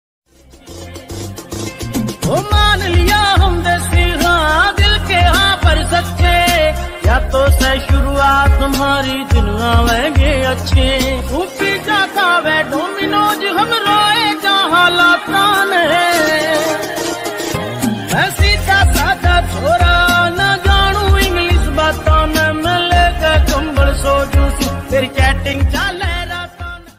Haryanvi Ringtone